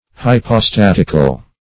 Hypostatic \Hy`po*stat"ic\, Hypostatical \Hy`po*stat"ic*al\, a.